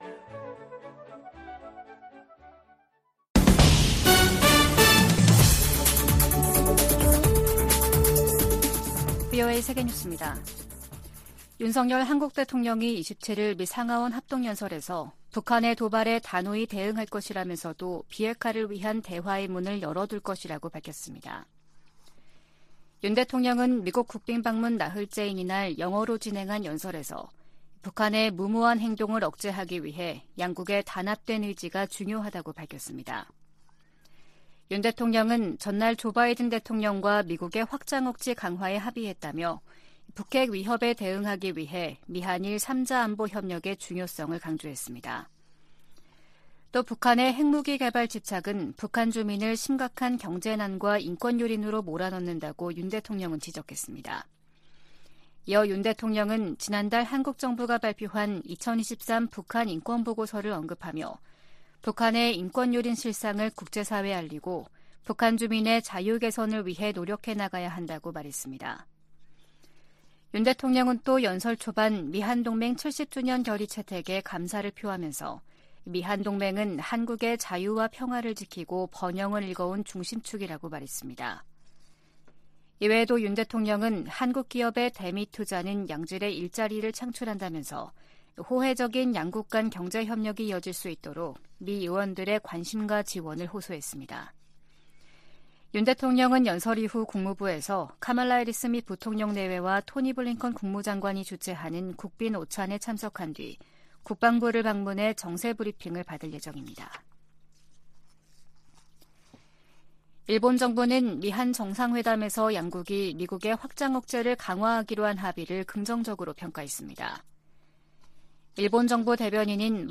VOA 한국어 아침 뉴스 프로그램 '워싱턴 뉴스 광장' 2023년 4월 28일 방송입니다. 조 바이든 미국 대통령과 윤석열 한국 대통령이 26일 백악관 회담에서 '워싱턴 선언'을 채택하고, 미한 핵협의그룹을 창설하기로 했습니다. 전문가들은 이번 정상회담에서 양국 관계가 '글로벌 포괄적 전략동맹'으로 격상을 확인했다고 평가했습니다.